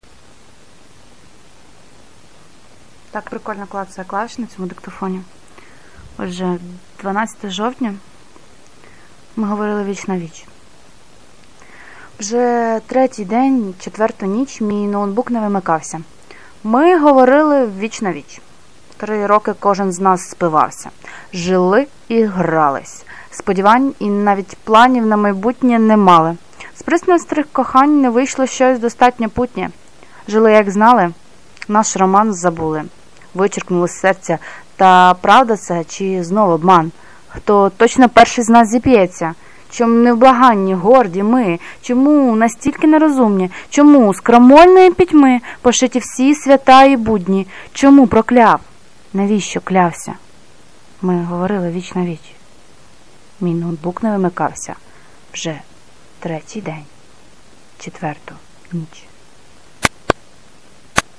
Ми говорили віч-на-віч (+диктофон)